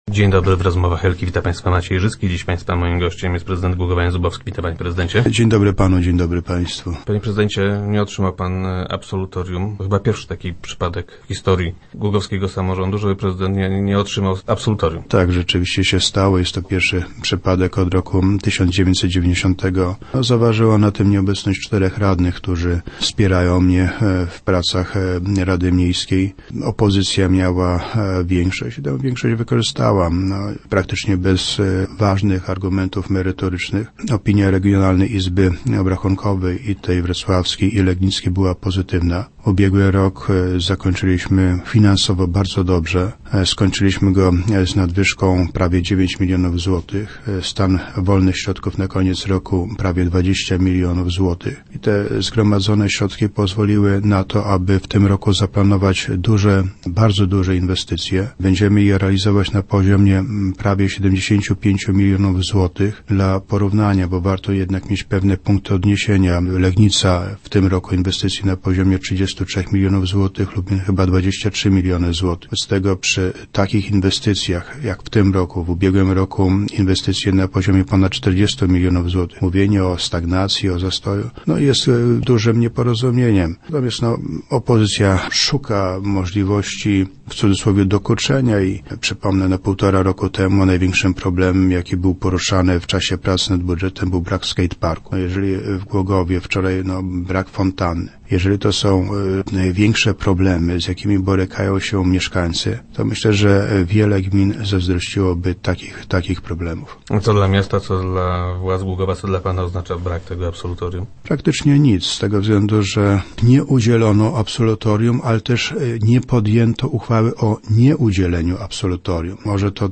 0511_zubowski_nowy.jpgPierwszy raz w historii głogowskiego samorządu prezydent miasta nie otrzymał absolutorium. - Zaważyła jednak na tym nie merytoryczna ocena wykonania budżetu, lecz chęć dokuczenia – stwierdził prezydent Jan Zubowski, który był gościem środowych Rozmów Elki.